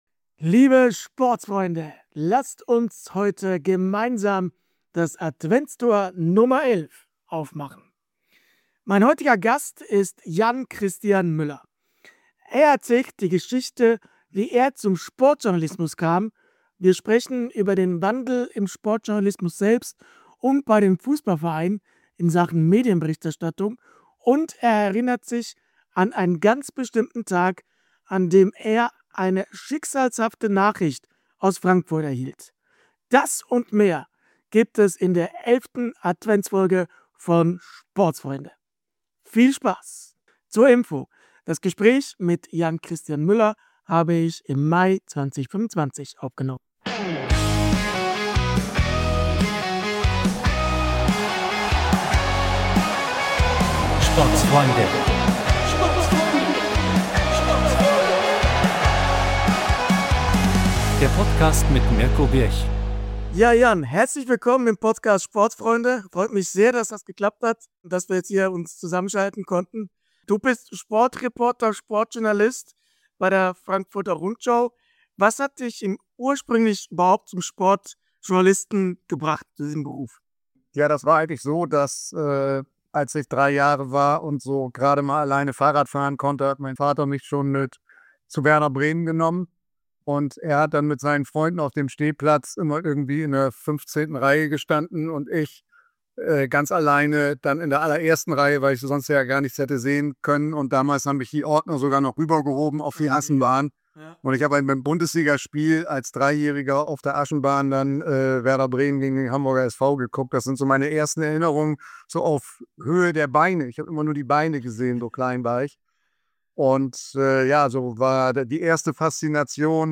Beschreibung vor 4 Monaten ADVENTSKALENDERFOLGE 11 bei SPORTSFREUNDE!